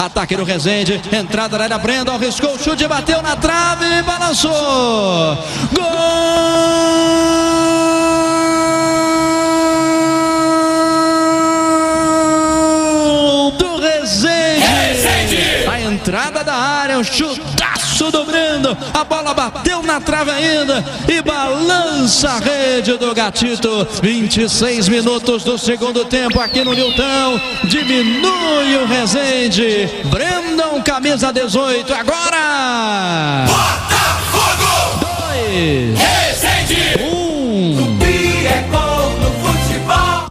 narração
GOL-BOTA-2-X-1-RESENDE-online-audio-converter.com_.mp3